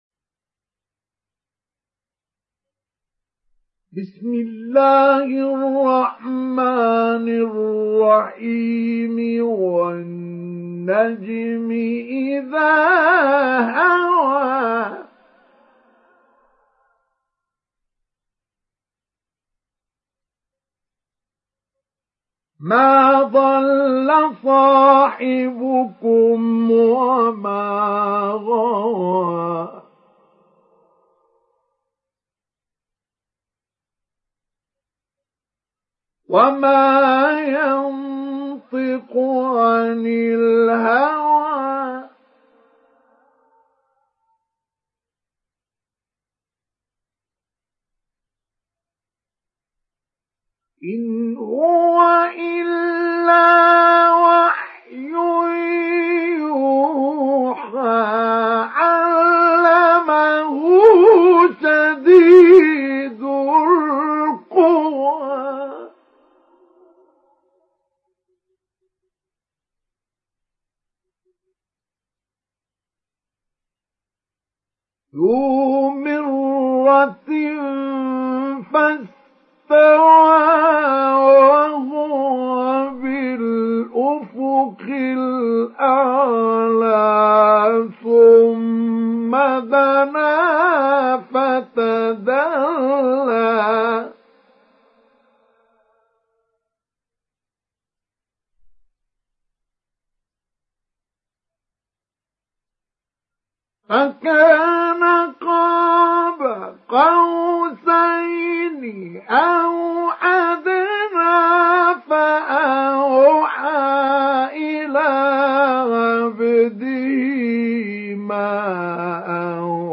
Sourate An Najm Télécharger mp3 Mustafa Ismail Mujawwad Riwayat Hafs an Assim, Téléchargez le Coran et écoutez les liens directs complets mp3
Télécharger Sourate An Najm Mustafa Ismail Mujawwad